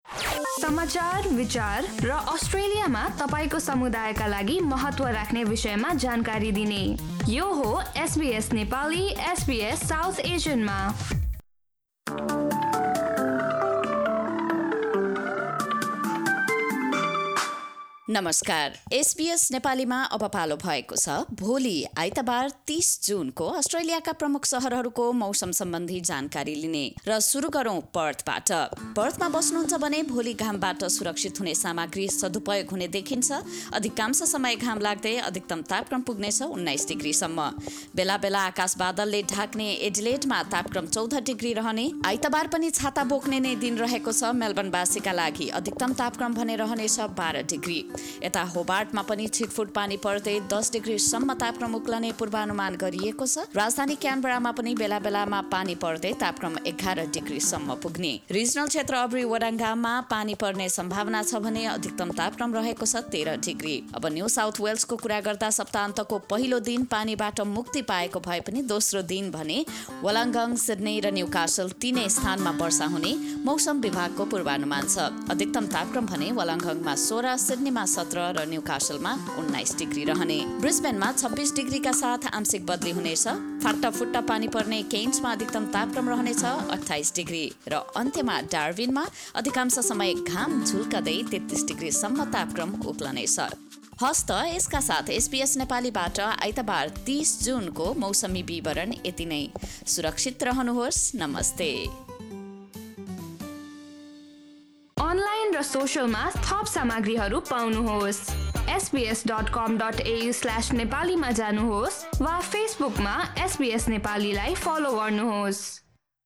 A quick Australian weather update in Nepali language.